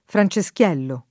fran©eSkL$llo] soprann. m. (di Francesco II re delle Due Sicilie, 1836-94) — raro, in forma più tosc., Franceschello [fran©eSk$llo]: cospirava pel ritorno di Franceschello [koSpir#va pel rit1rno di fran©eSk$llo] (Verga) — cfr. speranziella